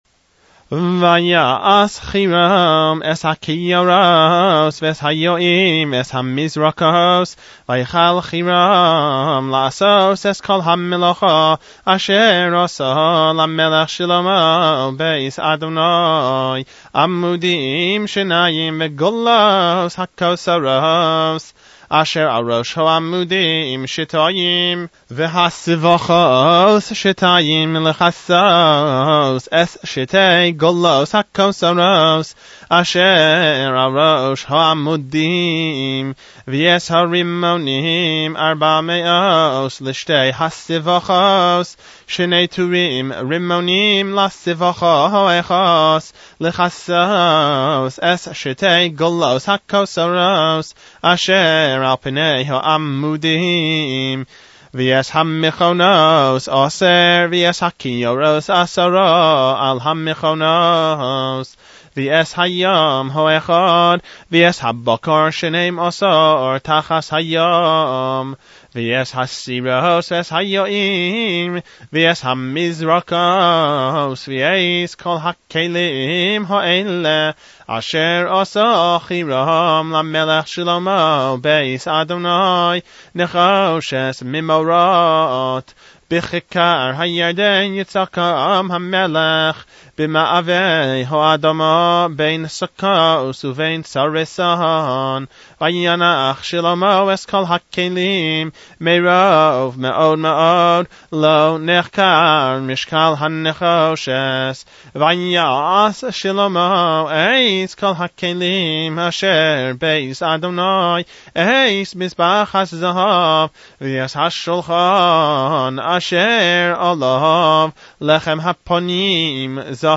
Leins Haftarah